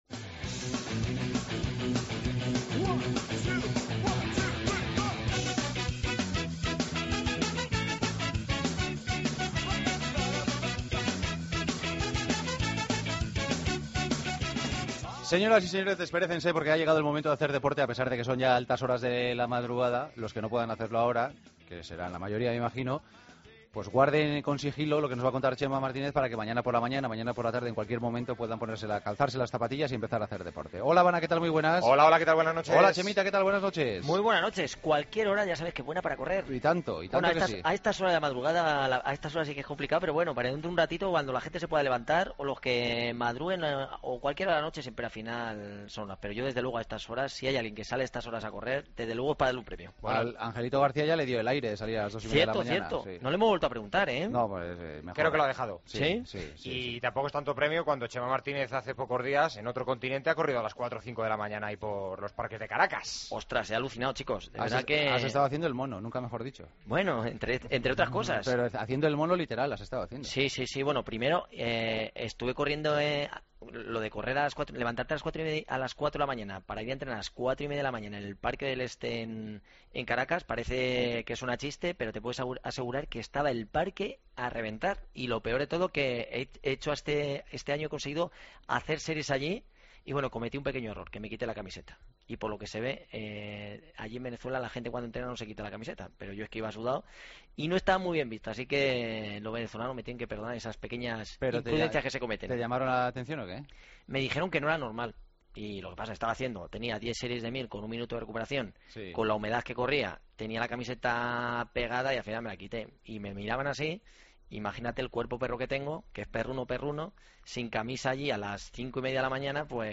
AUDIO: Escucha la consulta semanal con nuestra atleta Chema Martínez. Y hablamos con Kilian Jornet, corredor de montaña.